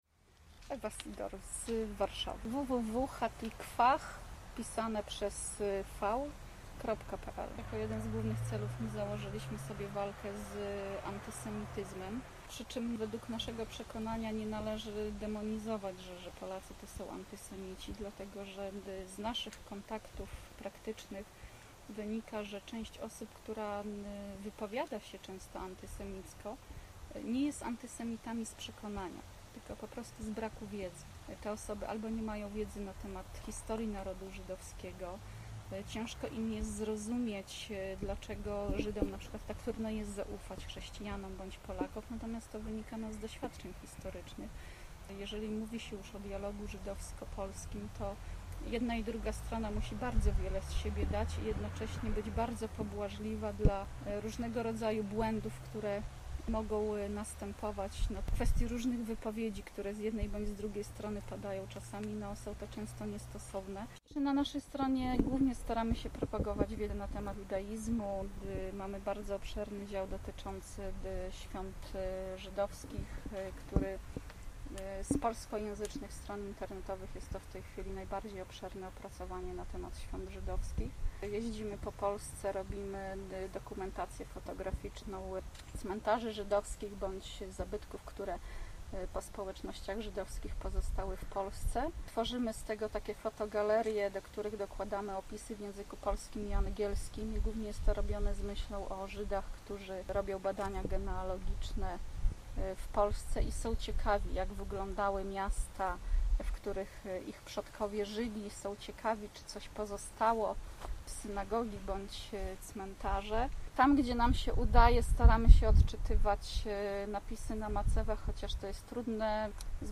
Historia mówiona: